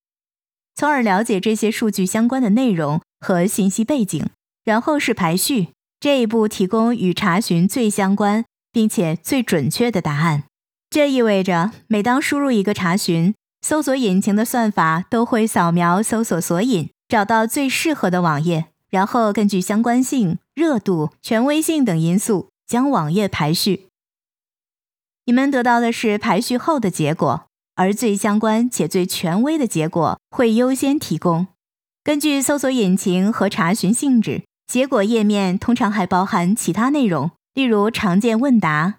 Chinese_Female_045VoiceArtist_2Hours_High_Quality_Voice_Dataset
Text-to-Speech